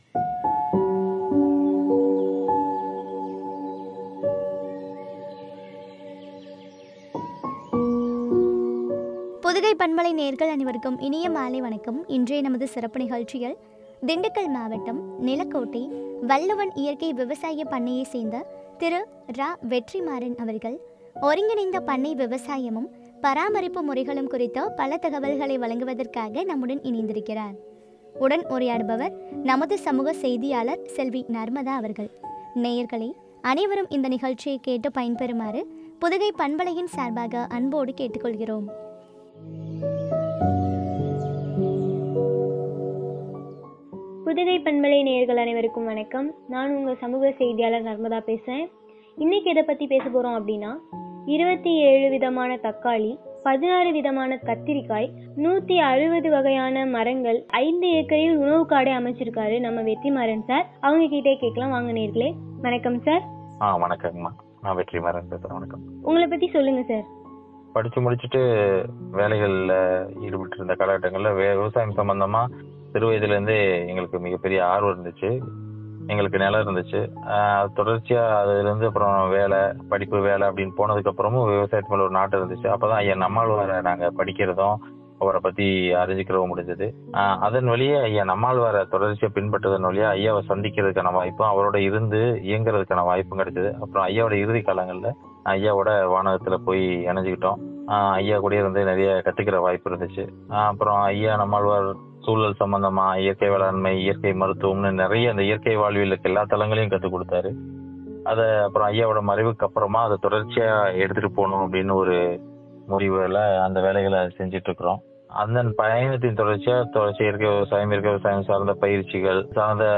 பராமரிப்பு முறைகளும் பற்றிய உரையாடல்.